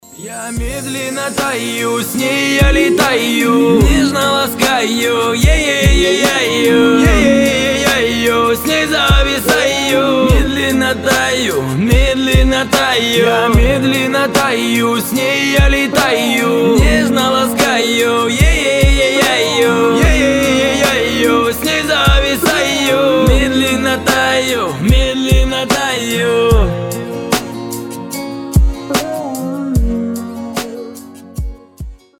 гитара
мужской голос
лирика
медленные